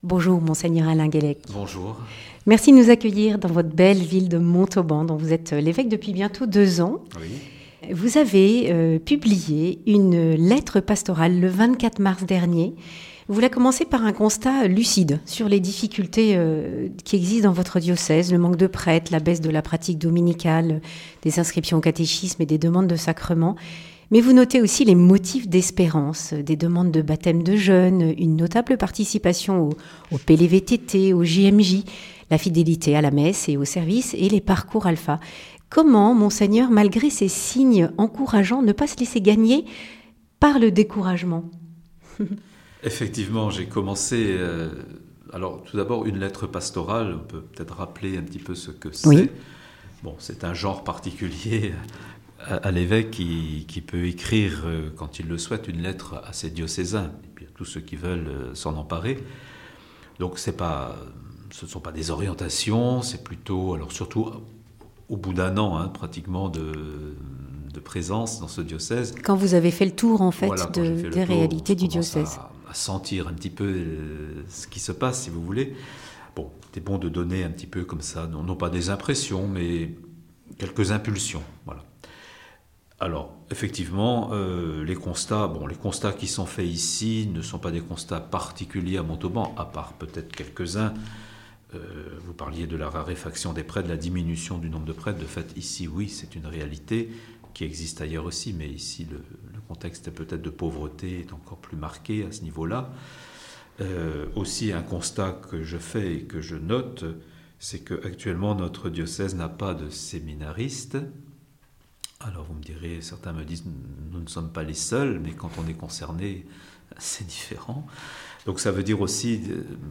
Deux ans après son arrivée dans le Diocèse de Montauban, Monseigneur Alain Guellec est au micro de Radio Présence pour sa première carte blanche.